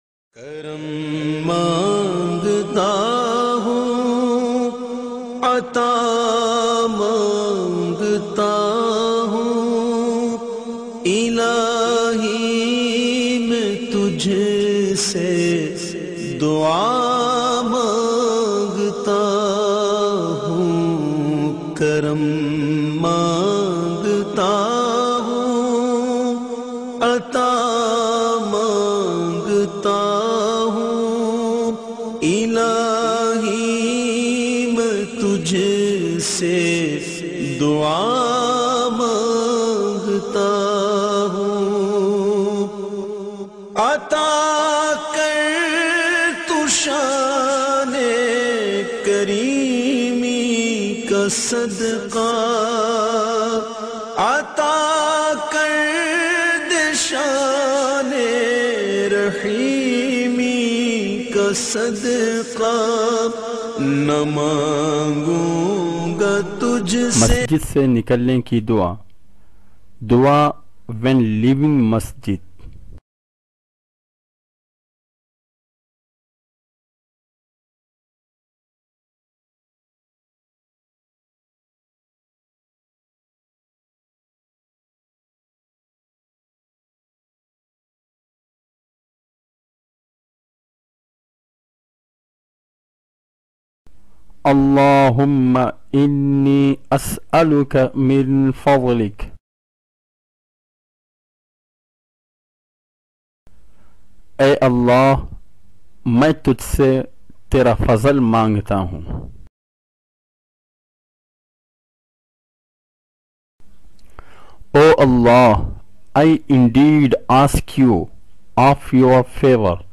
Dua When Leaving the Mosque, Masjid se nikalne ki dua, listen online mp3 arabic recitation of Dua When Leaving the Mosque.